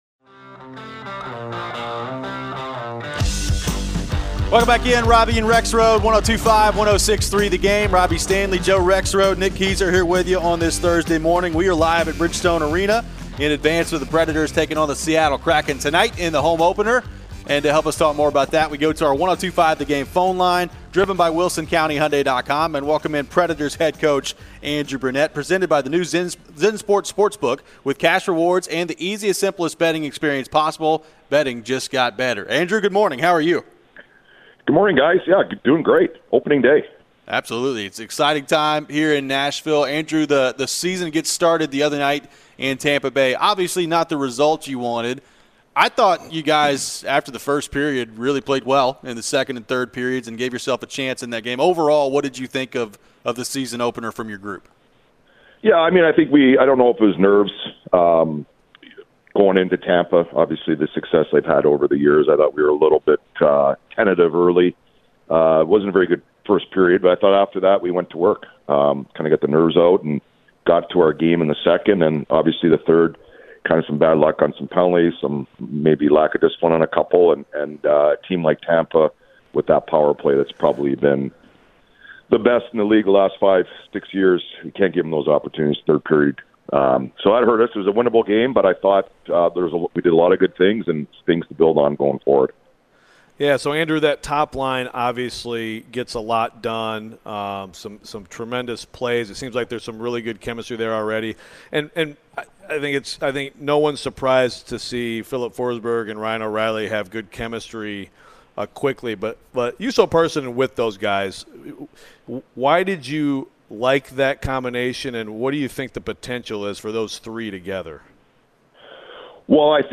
Andrew Brunette Interview (10-12-23)